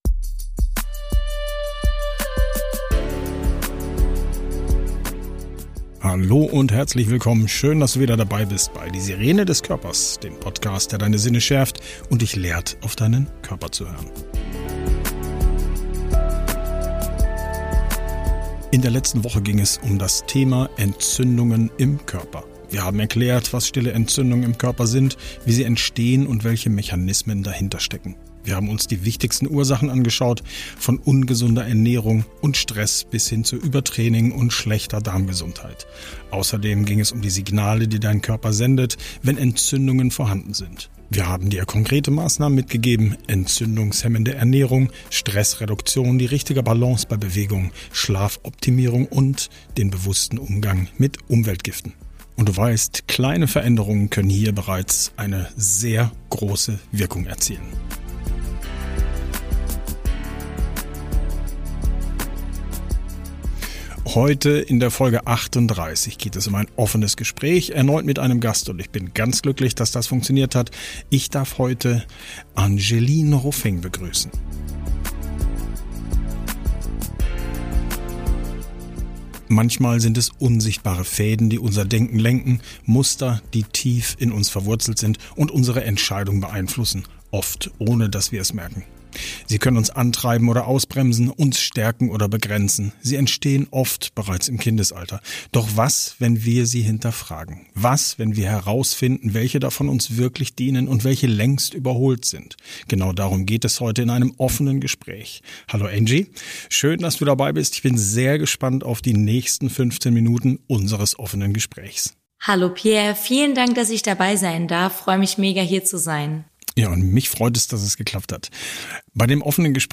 In dieser Folge führen wir ein offenes Gespräch mit einem Gast über die tiefgreifende Wirkung unserer Überzeugungen.